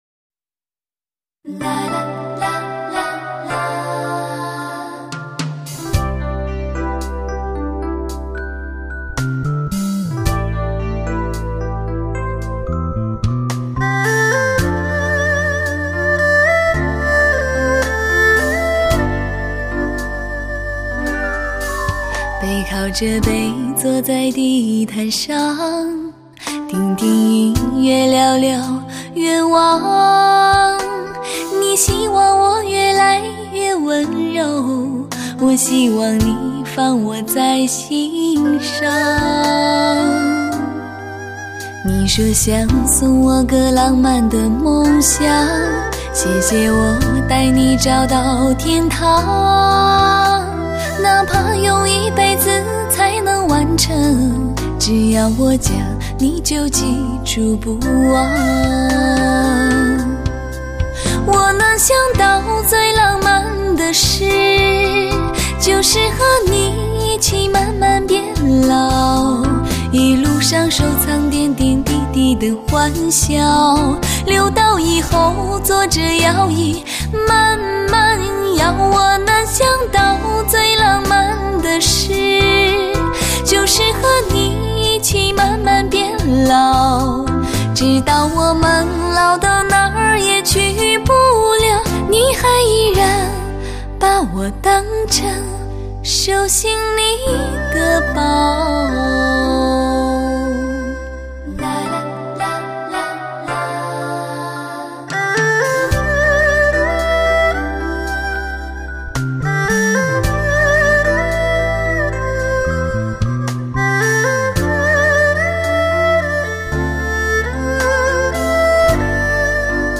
类型: 天籁人声